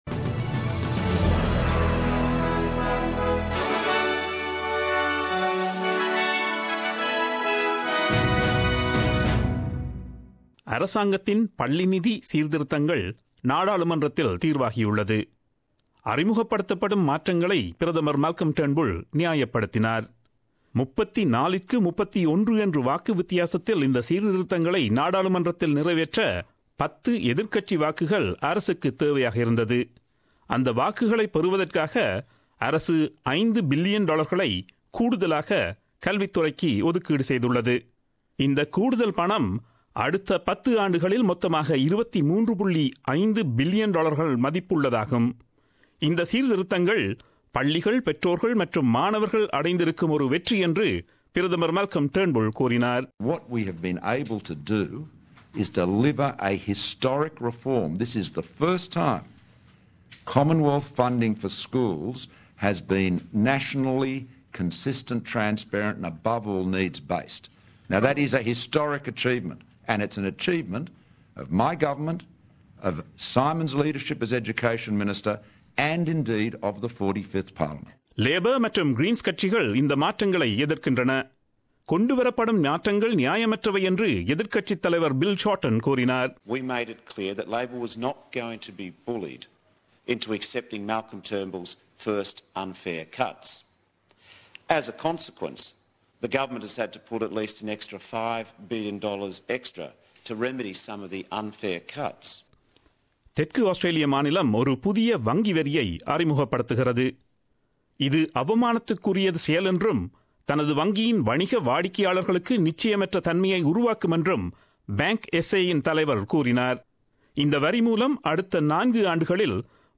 Australian news bulletin aired on Friday 23 June 2017 at 8pm.